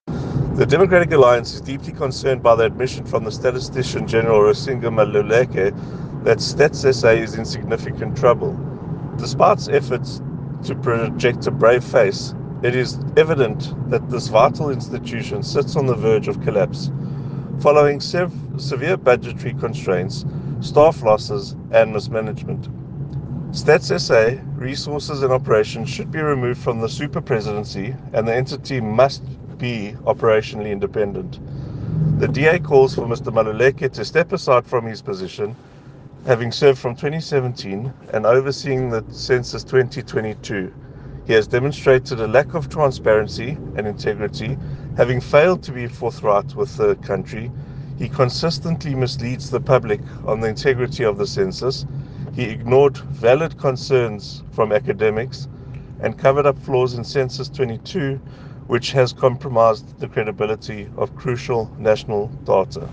soundbite by Darren Bergman MP.